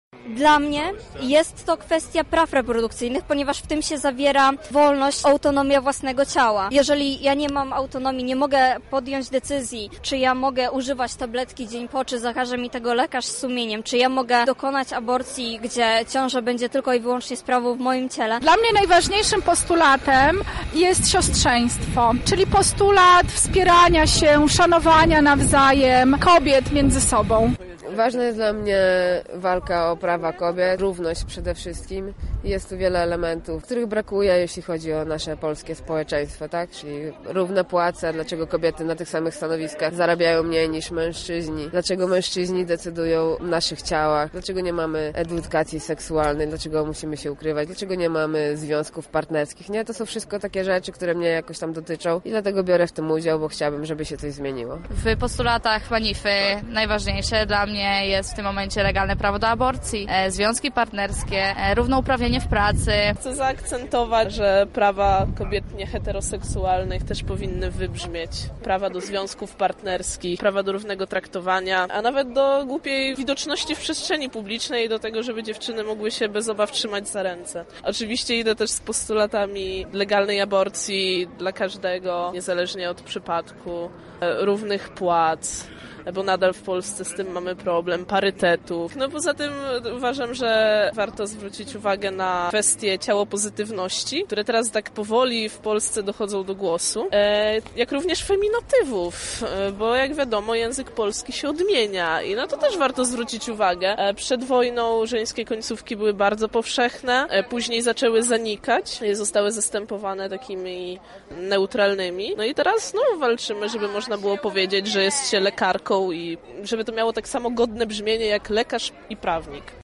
W sobotę odbyła się lubelska Manifa.
Posłuchajmy więc czego domagają się uczestniczki lubelskiej Manify: